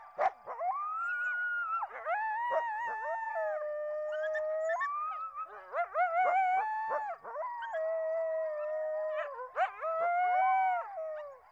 Койот (Canis latrans).